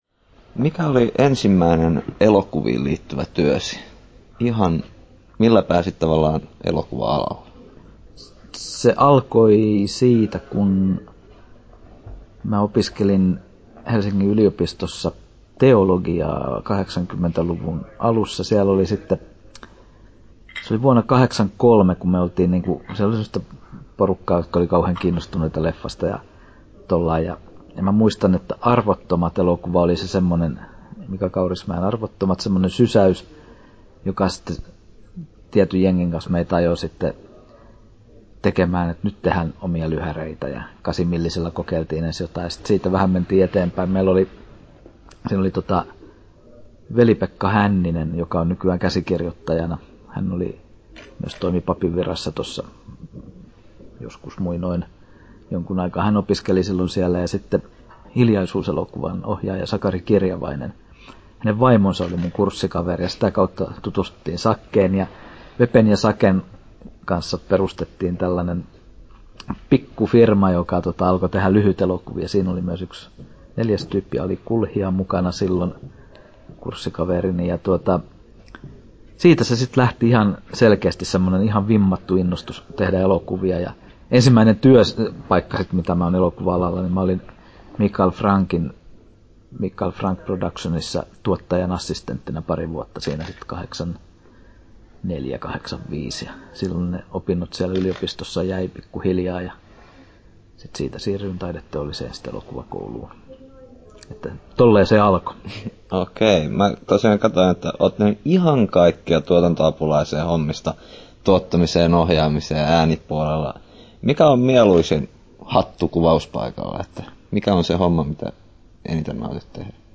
16'16" Tallennettu: 7.12.2011, Turku Toimittaja